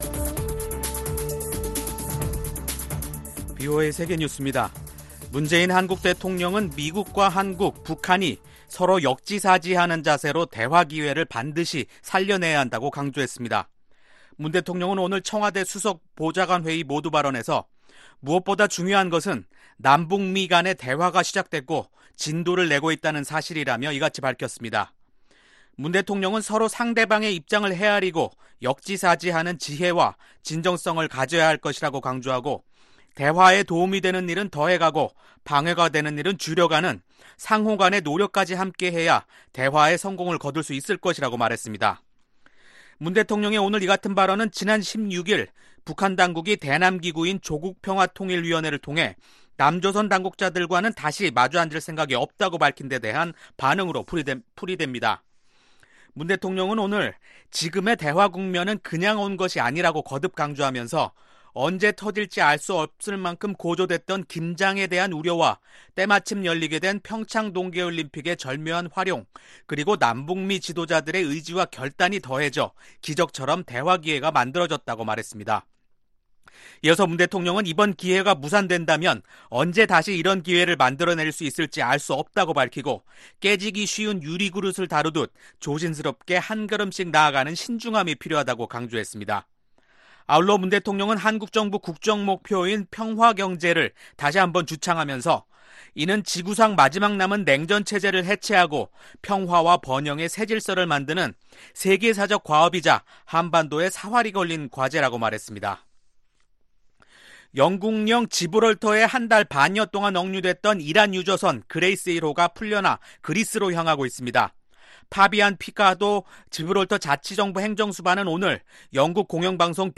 VOA 한국어 간판 뉴스 프로그램 '뉴스 투데이', 2019년 8월 15일 2부 방송입니다. 스티븐 비건 미 국무부 대북특별대표가 이번주 일본과 한국을 방문합니다. 국무부는 북한과 대화할 준비가 돼 있다고 밝혔습니다. 북한 정권을 상대로 민사소송을 제기한 푸에블로 호 승조원들과 가족들이 재판부에 ‘궐석판결’을 요구했습니다.